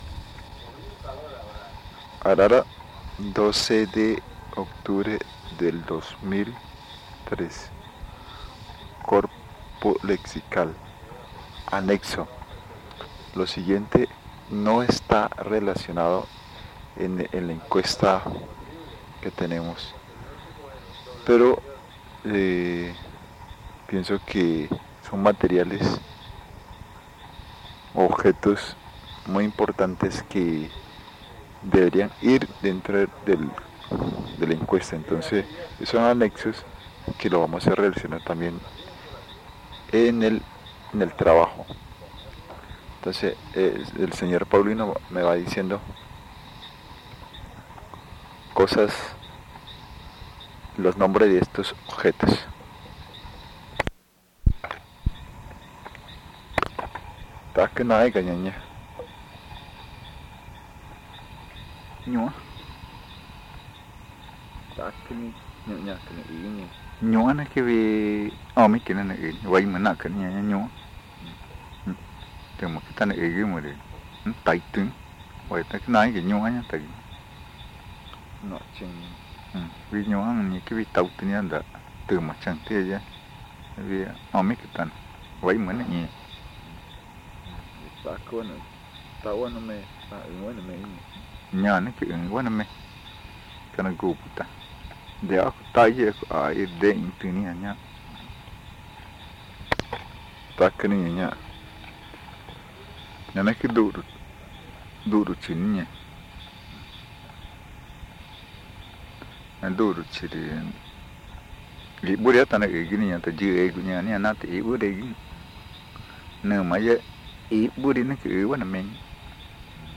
Encuesta léxica y gramatical en Arara (Río Amazonas) - casete 3
Este casete es el tercero de una serie de tres casetes que se grabaron en torno a la variedad magütá hablada en Arara. El audio contiene los lados A y B.